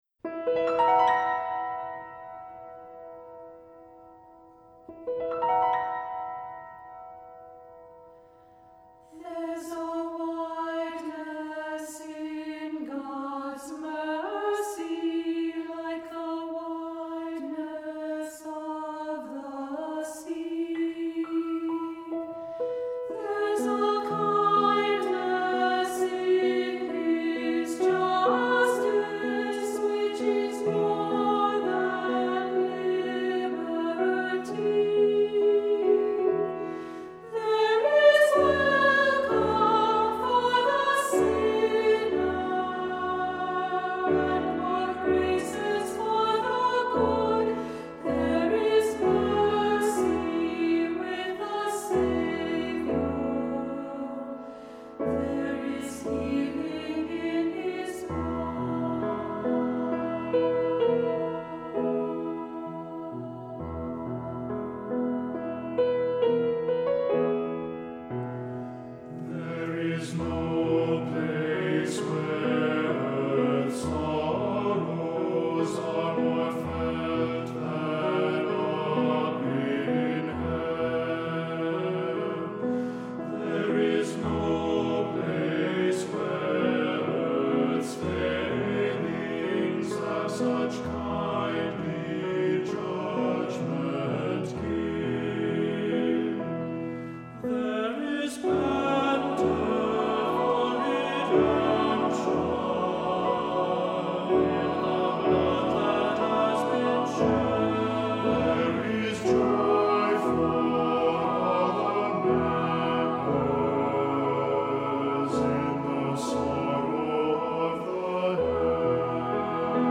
Recording - All Voices